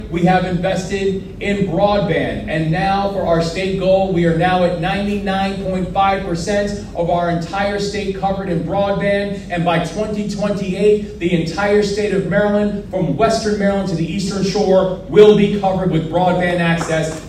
One of the points that Governor Wes Moore brought out during remarks at the Appalachian Regional Commission conference was the expansion of broadband. Moore said that economic growth and technological advancement cannot happen without investment in Appalachia…